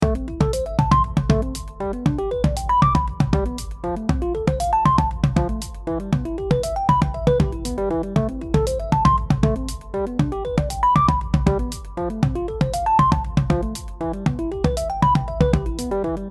Space travel music?